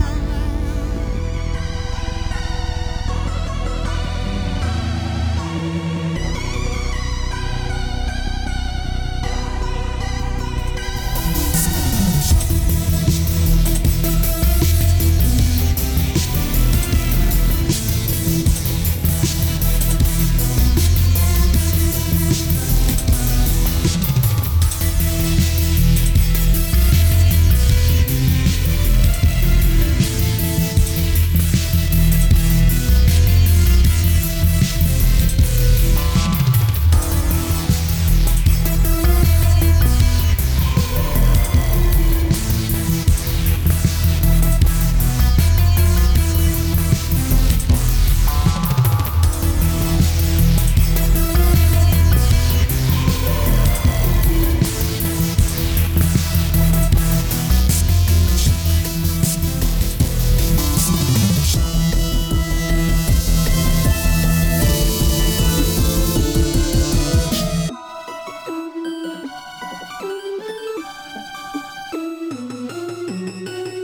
Sinister theme with a few variations.